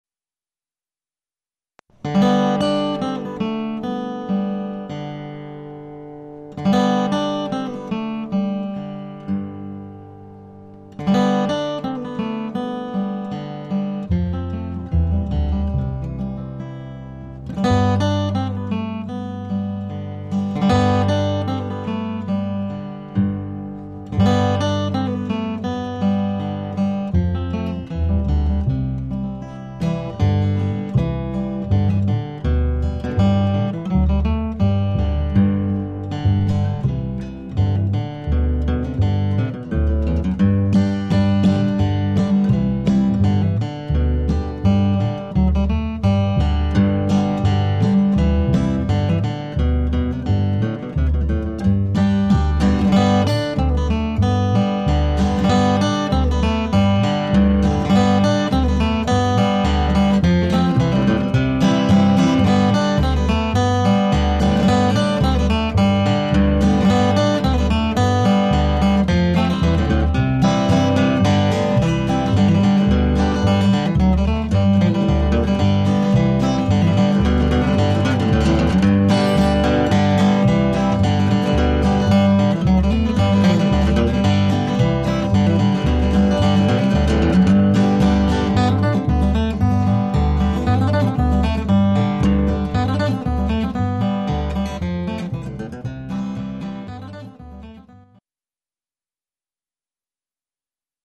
He is an accomplished bluegrass banjoist and “thumb-picking” guitar player and has appeared as a featured performer at the Philadelphia Folk/Bluegrass Festival as well as appeared on numerous recordings and performances throughout the United States and abroad.
guitar and 5-string banjo but can hack at